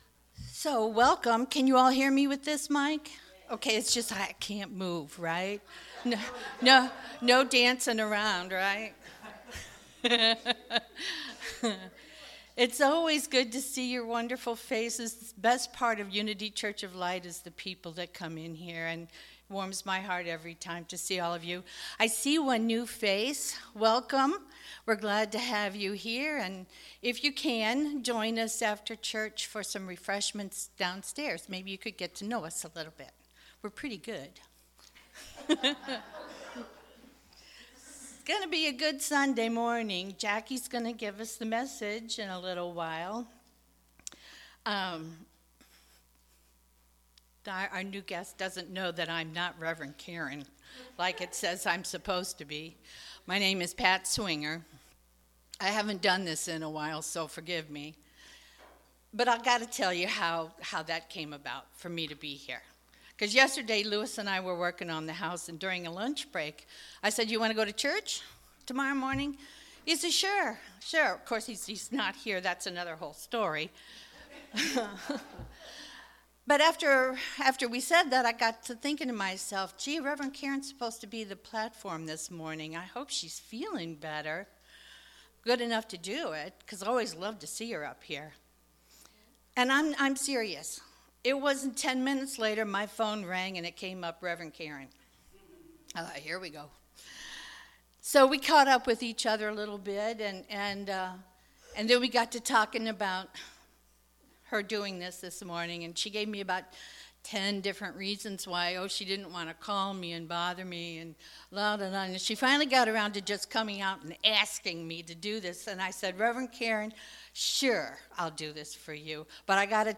Sermons 2023 Date